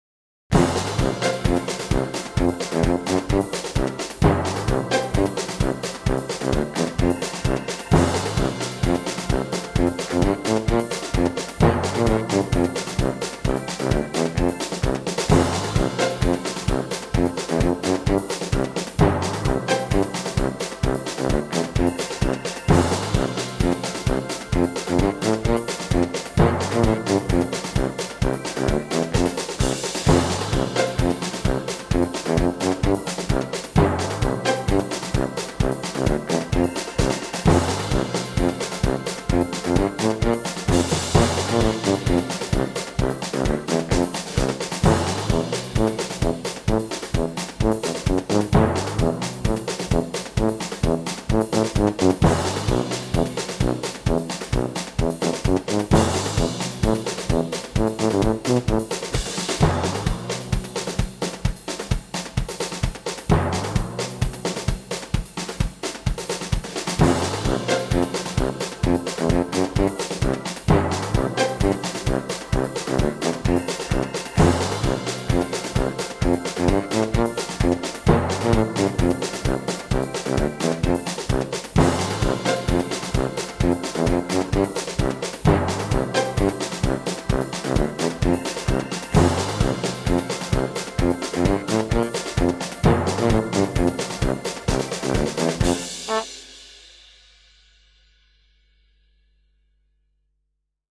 Как в цирковом представлении